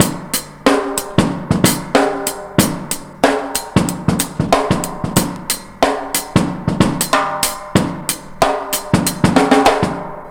Index of /90_sSampleCDs/Best Service ProSamples vol.24 - Breakbeat [AKAI] 1CD/Partition B/ONE HAND 093